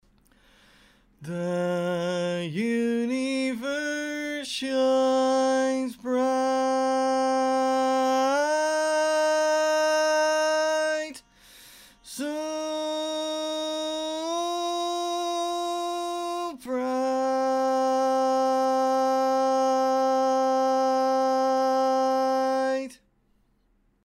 Key written in: B♭ Major
Type: Barbershop
Each recording below is single part only.
Other part 1: